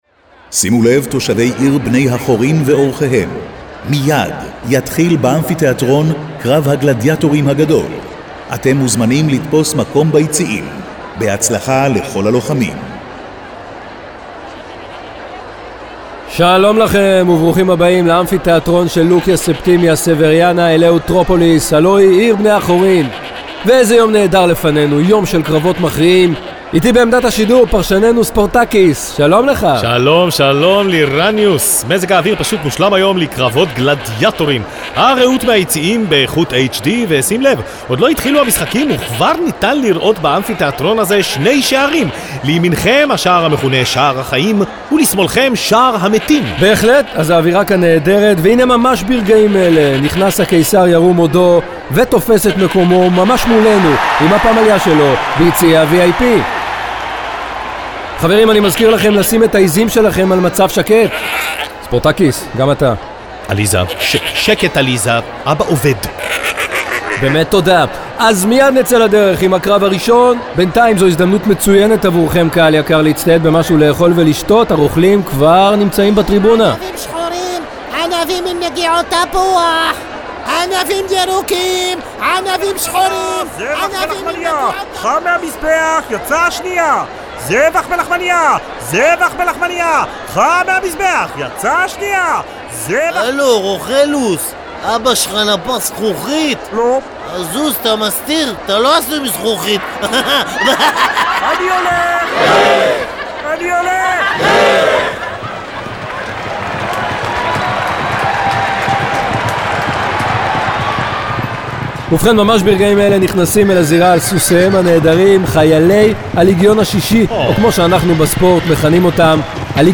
בית גוברין חזיון קולי